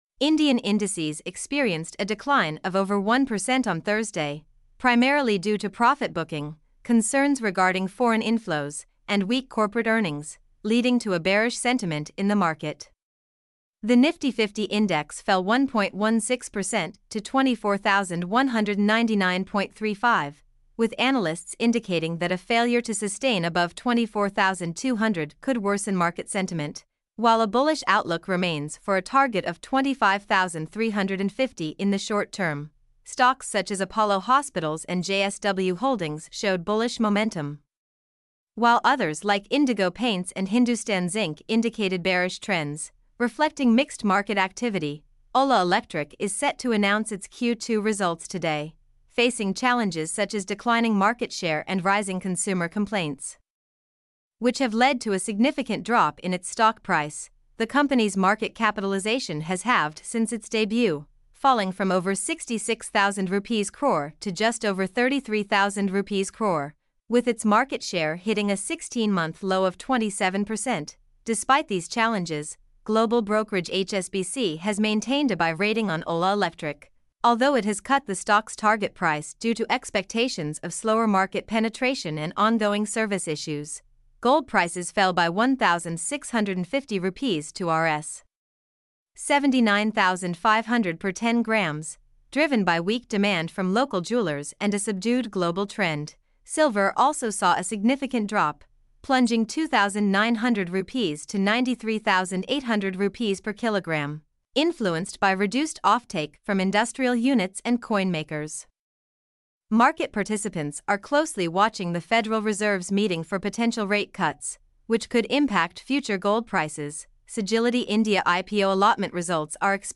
mp3-output-ttsfreedotcom-25.mp3